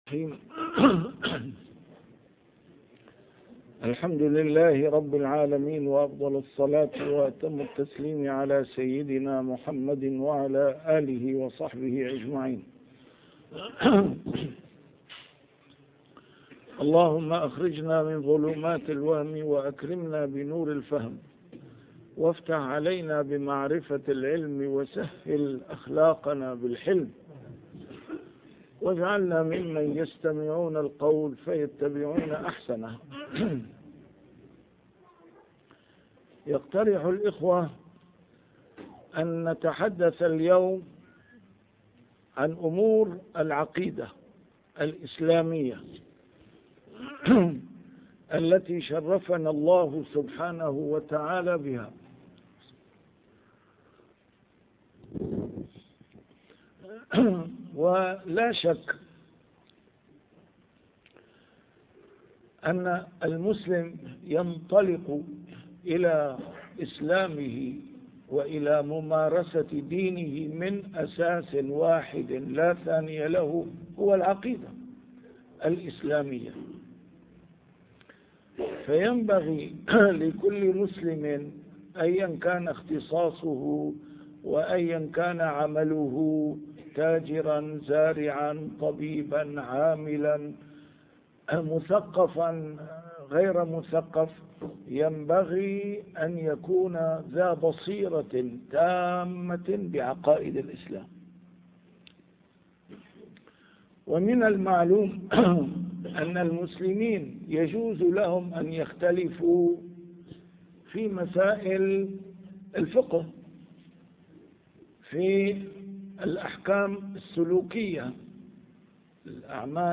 نسيم الشام › A MARTYR SCHOLAR: IMAM MUHAMMAD SAEED RAMADAN AL-BOUTI - الدروس العلمية - محاضرات متفرقة في مناسبات مختلفة - لُبُّ العقيدة الإسلامية | محاضرة في ألمانيا